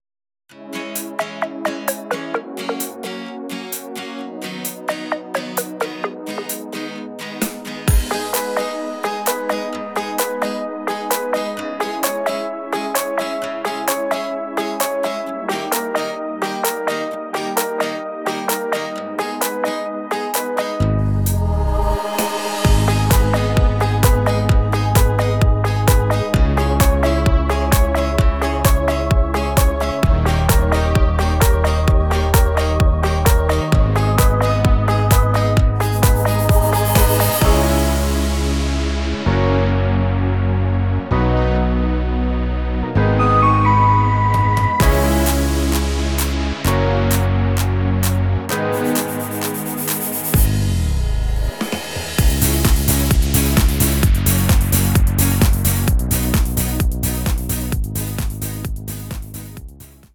Duett